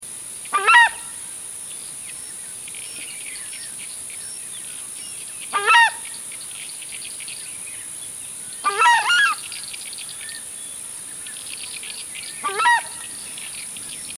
Звук пение птиц на звонок - Southern Screamer (chauna torquata)
Отличного качества, без посторонних шумов.
924_southern-scream.mp3